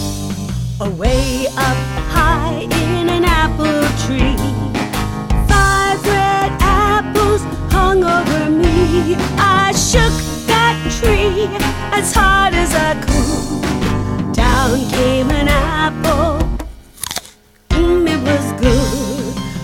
Genre: Children's Music.